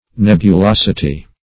Nebulosity \Neb`u*los"i*ty\, n. [L. nebulositas: cf. F.